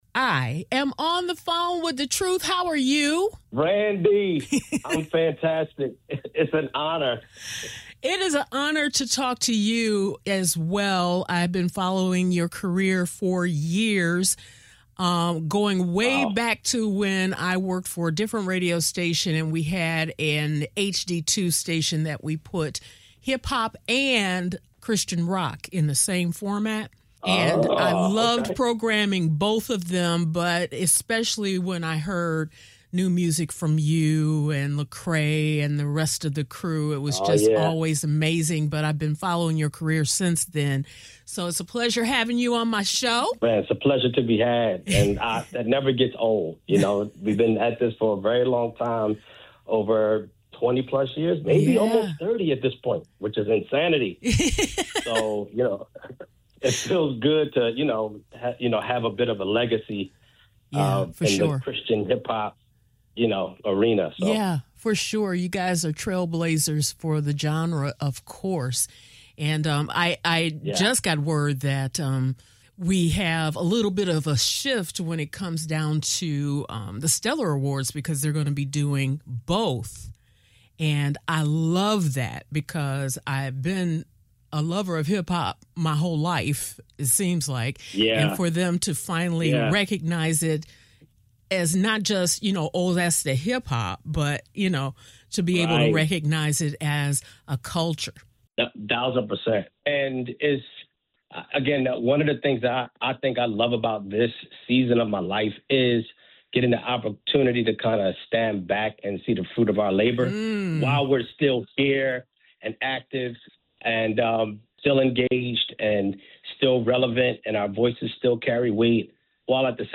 Recently, he joined me on the phone to talk about where that journey has taken him and what’s next.